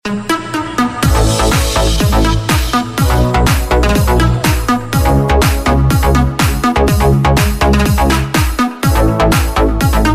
Custom Cutter Suction Dredger – sound effects free download